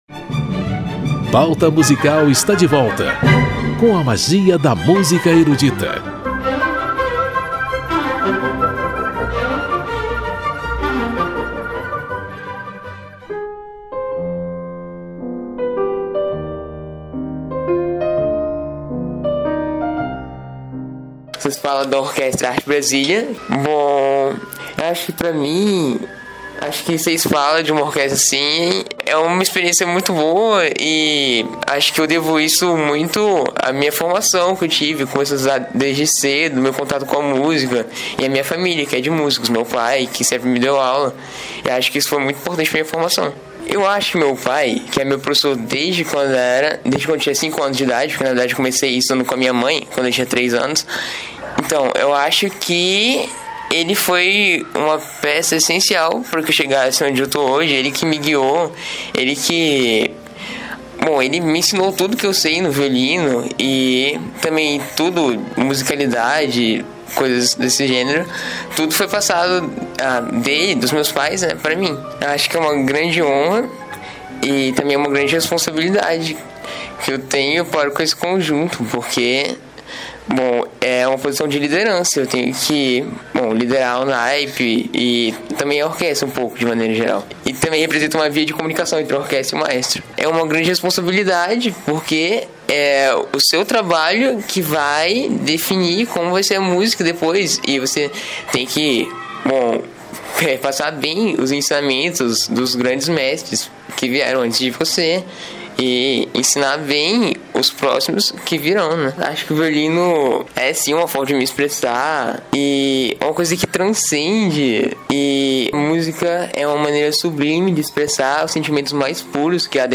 em entrevista exclusiva.